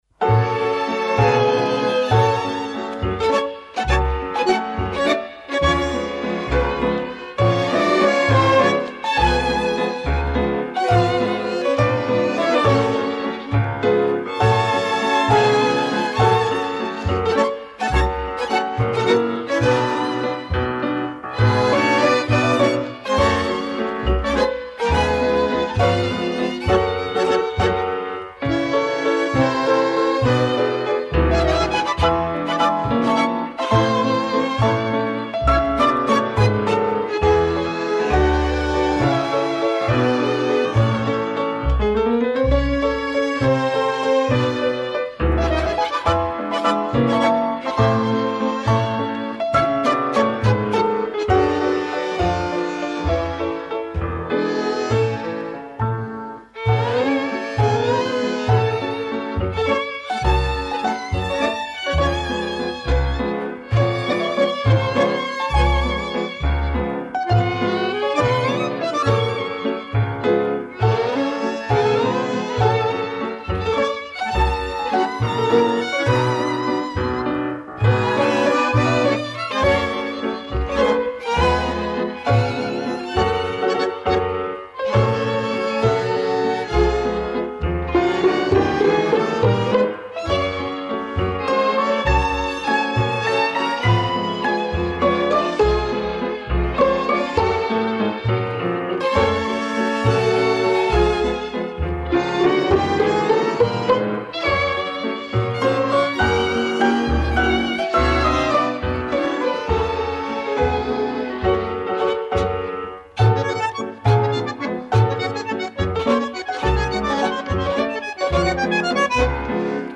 La tanda di Vals